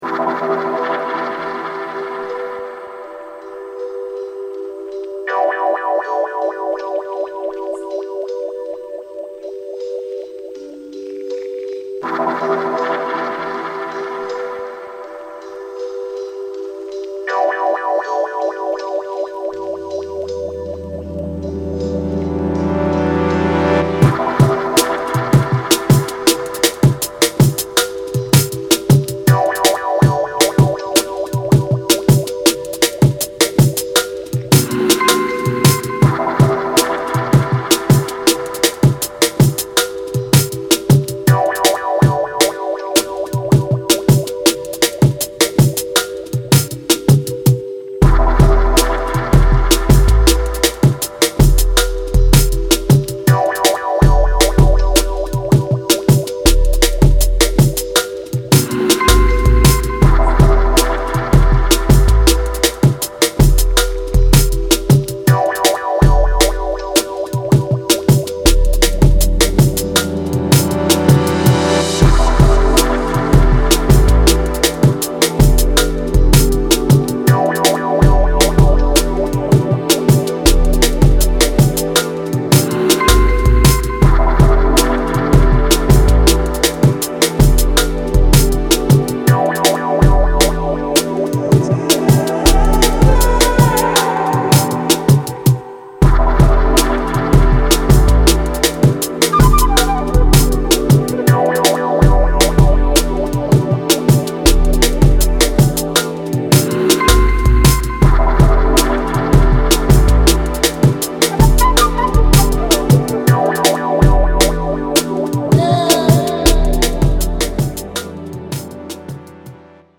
メロウジャングル